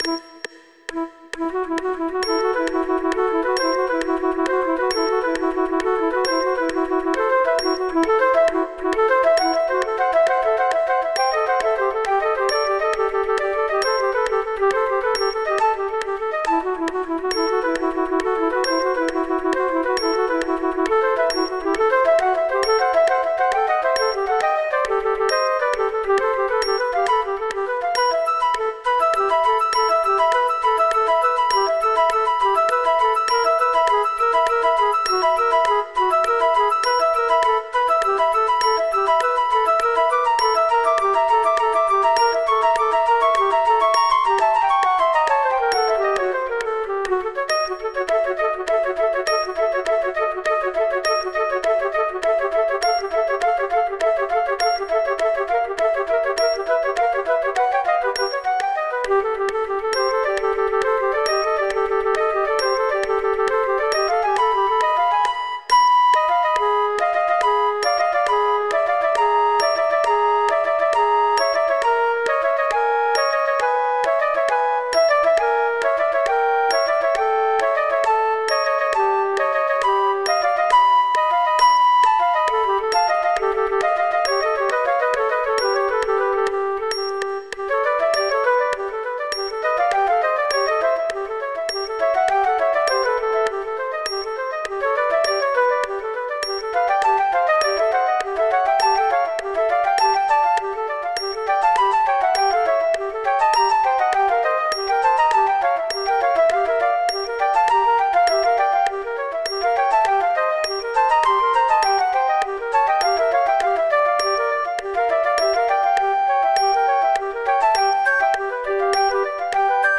This trio for three flutes is full of aural illusions.
With metronome clicks (and apologies for a strange metronome artefact right at the beginning),
with the second flute missing there are slow,